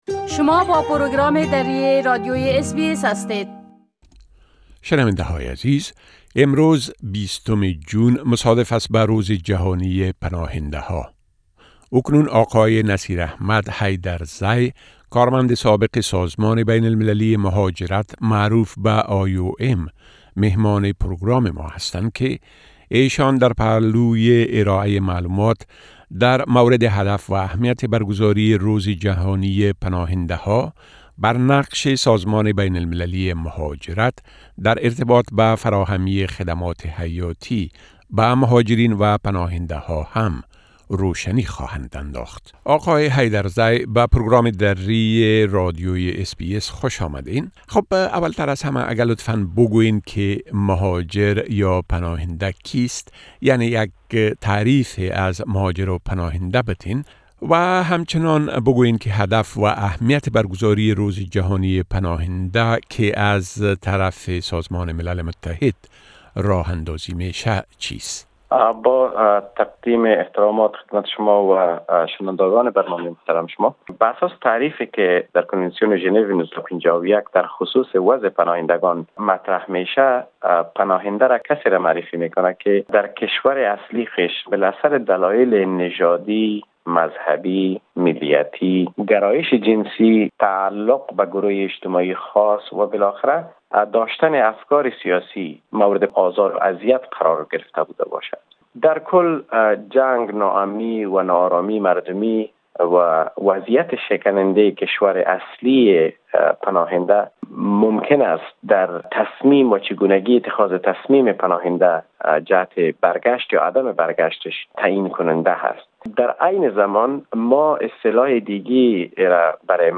در این گفت‌وگو: پناهنده کیست، مهاجرت چیست؟